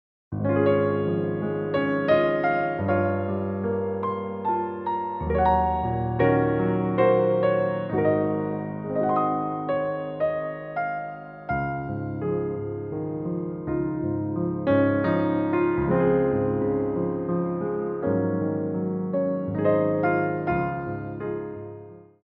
6/8 (8x8)